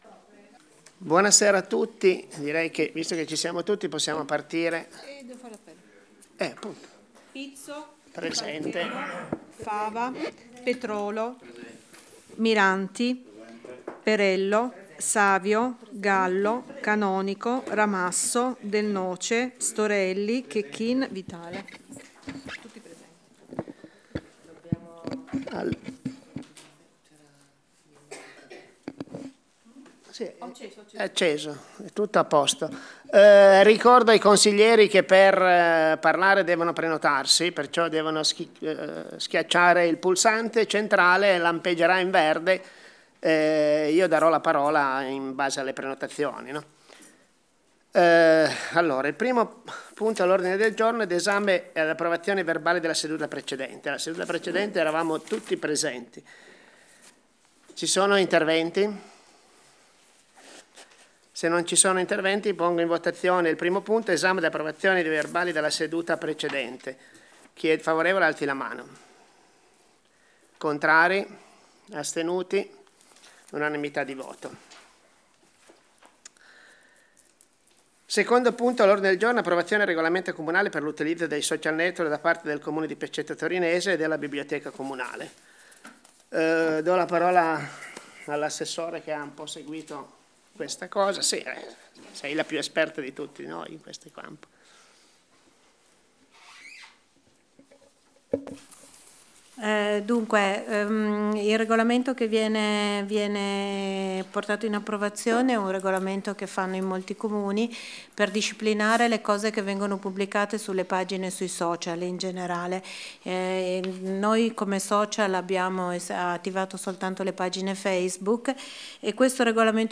Registrazione Consiglio comunale Comune di Pecetto Torinese